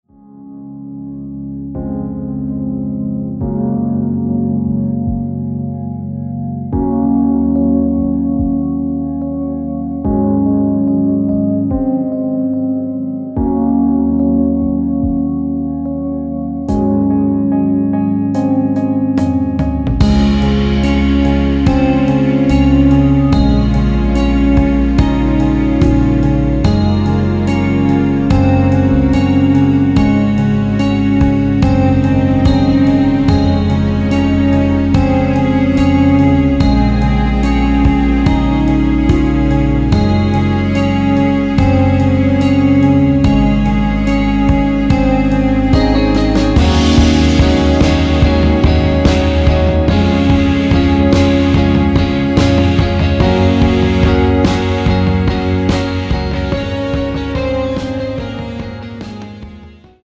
원곡 6분1초에서 4분 41초로 짧게 편곡된 MR입니다.
원키에서(-1)내린 (1절앞+후렴)으로 진행되는 MR입니다.
Db
앞부분30초, 뒷부분30초씩 편집해서 올려 드리고 있습니다.